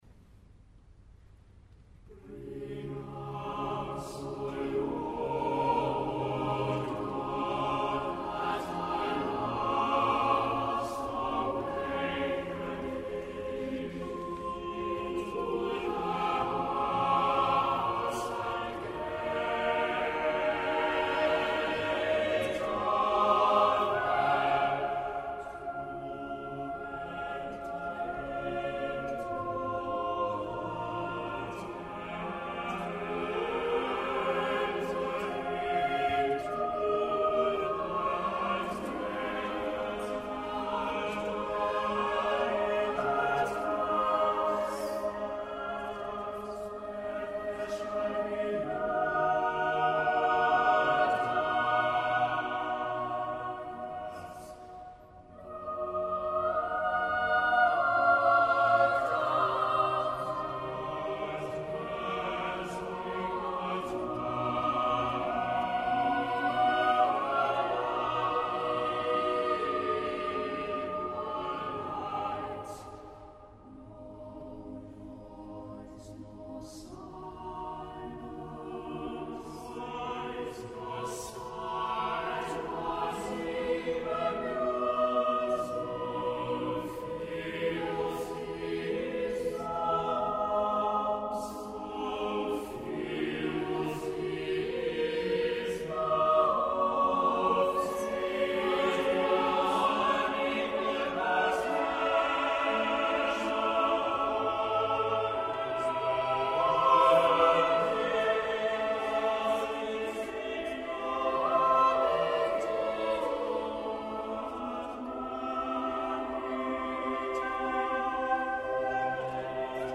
Easter from King’s College, Cambridge
17        Choir: